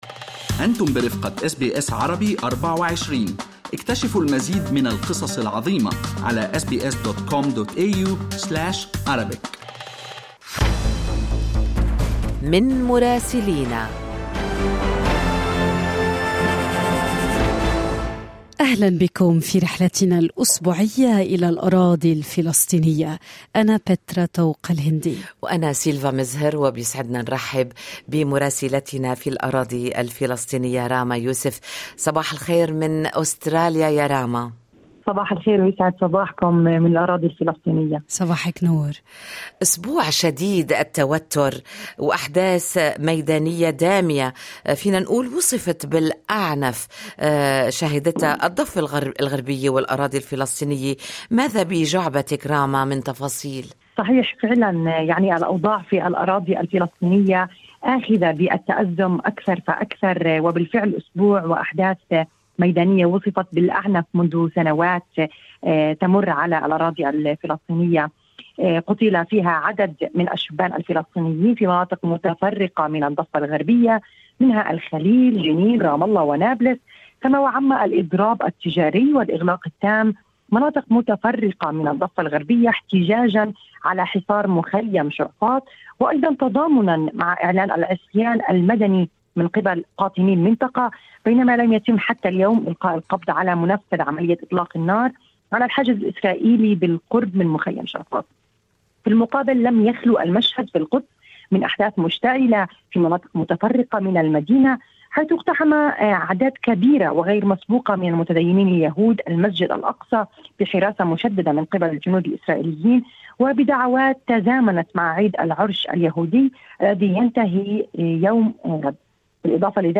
يمكنكم الاستماع إلى التقرير الصوتي من رام الله بالضغط على التسجيل الصوتي أعلاه.